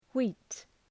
Προφορά
{wi:t}